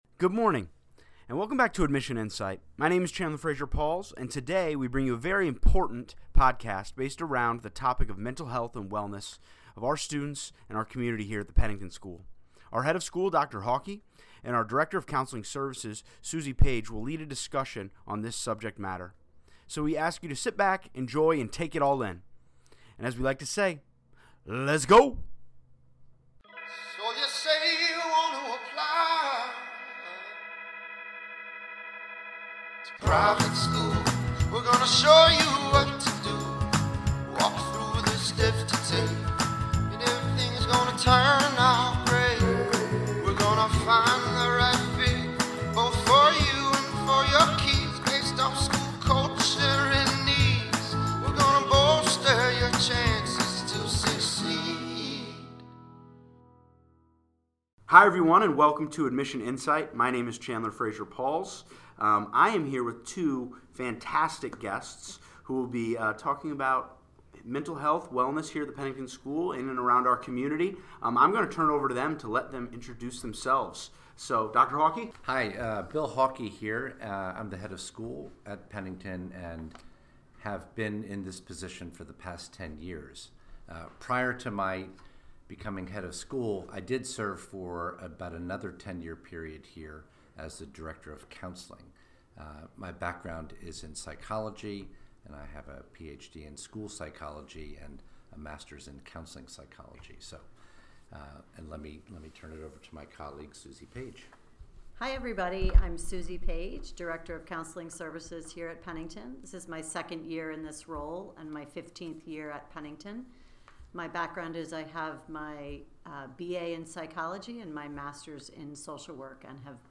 A Discussion Around Mental Health & Wellness!